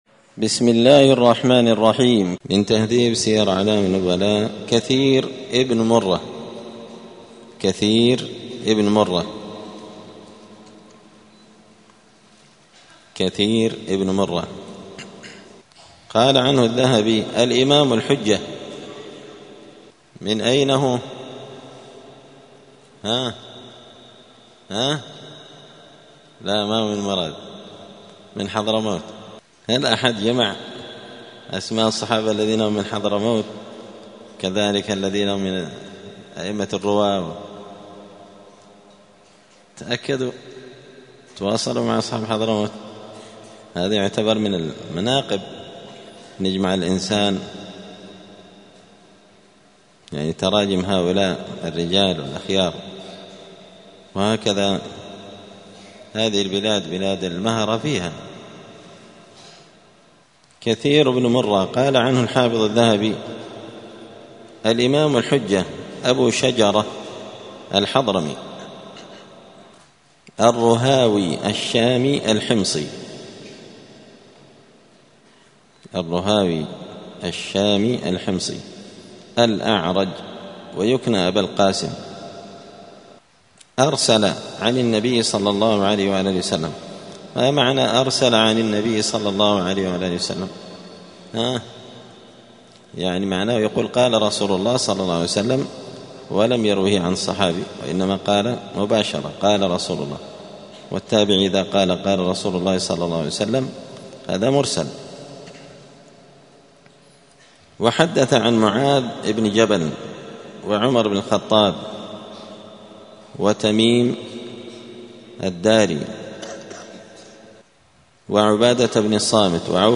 قراءة تراجم من تهذيب سير أعلام النبلاء
دار الحديث السلفية بمسجد الفرقان قشن المهرة اليمن